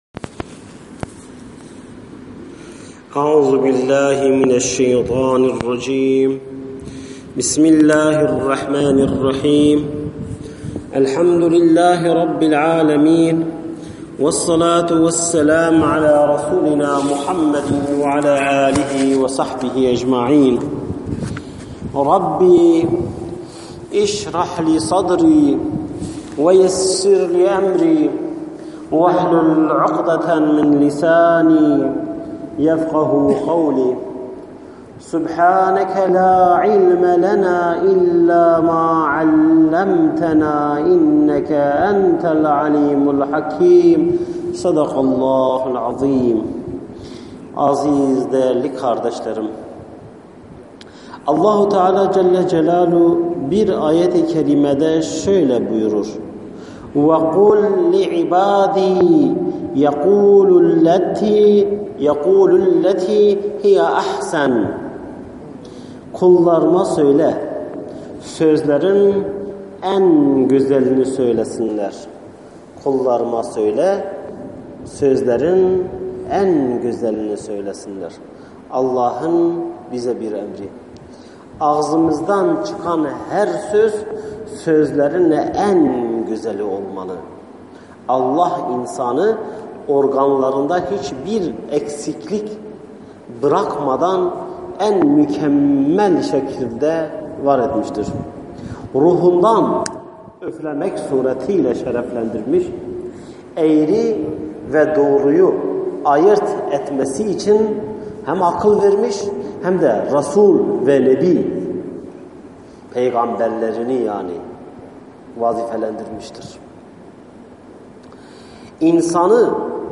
SESLİ SOHBETLER